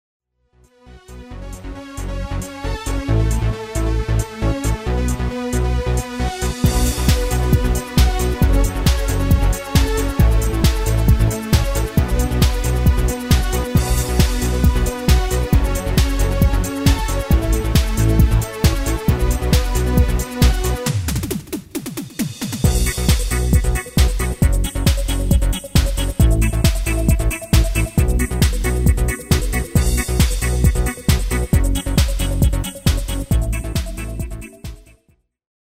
Demo/Koop midifile
Genre: Dance / Techno / HipHop / Jump
- Géén tekst
- Géén vocal harmony tracks
Demo = Demo midifile